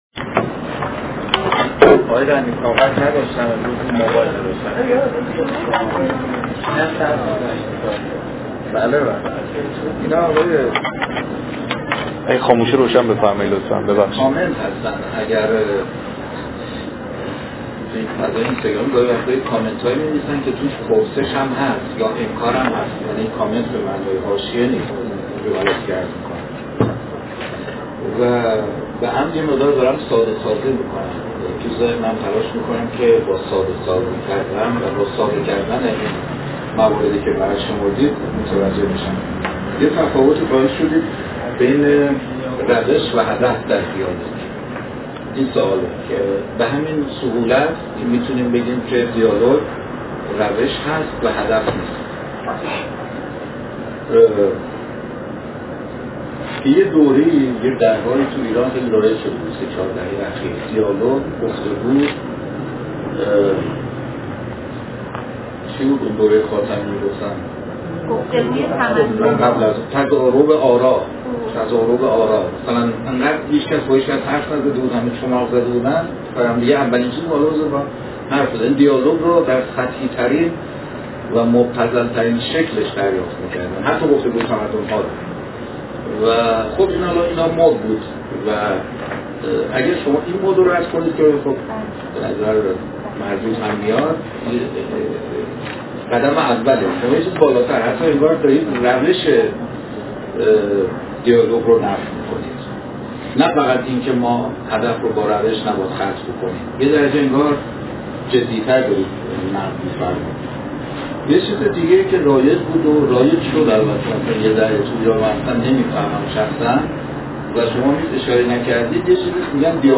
بحث و گفت‌وگو